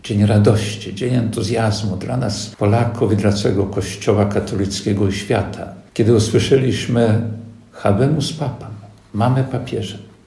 – Byłem wtedy diakonem w seminarium misyjnym w Pieniężnie – wspomina biskup ełcki ks Jerzy Mazur.